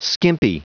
Prononciation du mot skimpy en anglais (fichier audio)
Prononciation du mot : skimpy